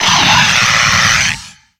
Cri de Bazoucan dans Pokémon Soleil et Lune.